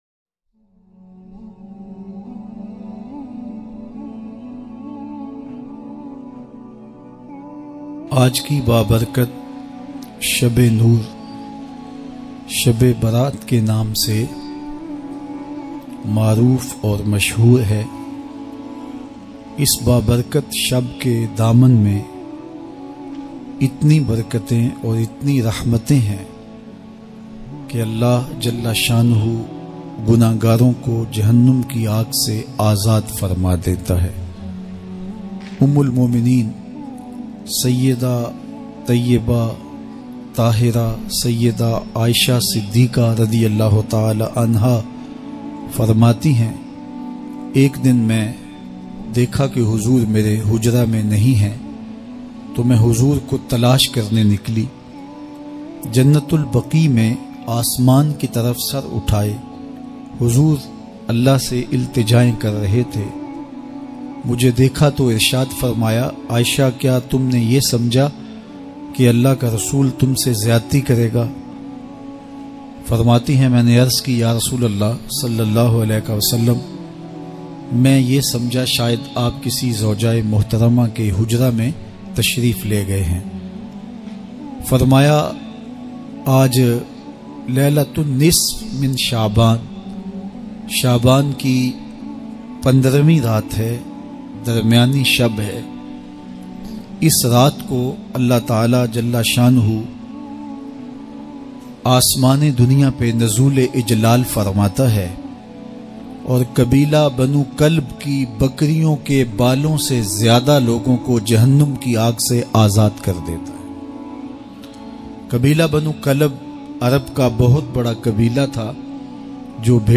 Shab E Barat Ki Raat Kiya Karna Chahye Bayan MP3 Download in best audio quality.